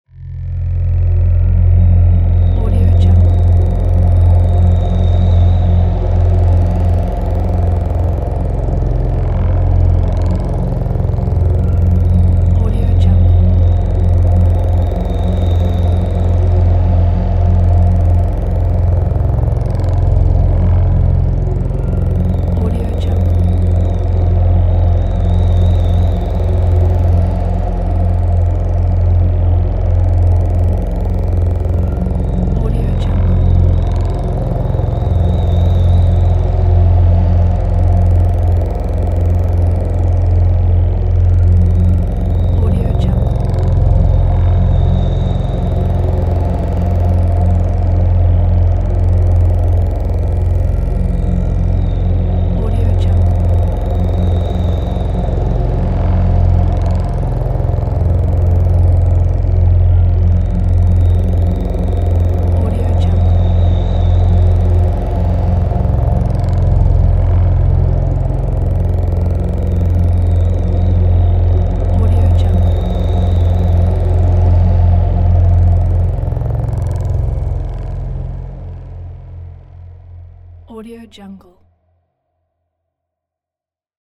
دانلود افکت صوتی صدای پهباد در تاریکی
تراک صوتی  Dark Drone یک گزینه عالی برای هر پروژه ای است که به انتقال و حرکت و جنبه های دیگر مانند پهپاد، استاتیک و پس زمینه نیاز دارد.